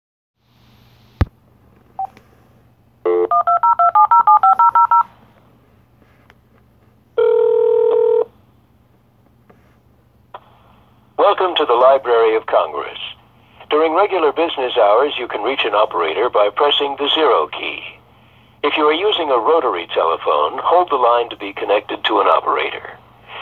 I made a call to the Library of Congress and this was the phone menu.